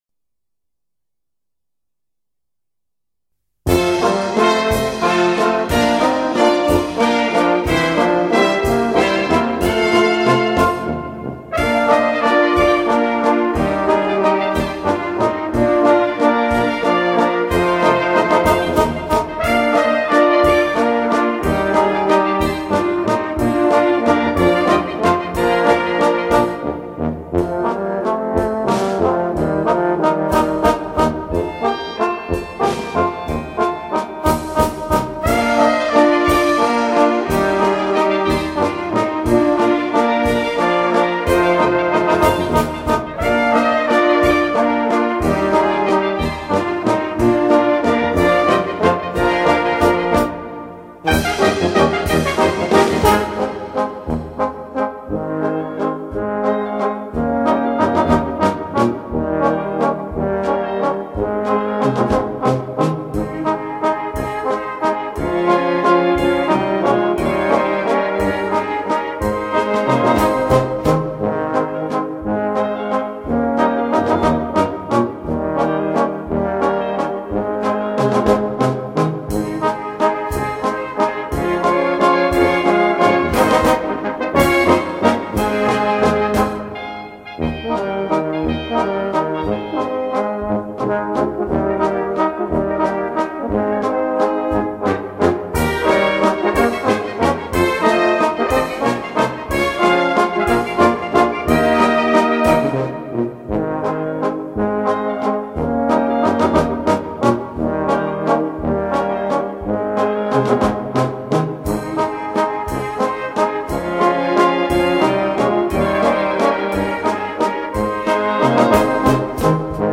Walzer, Orchester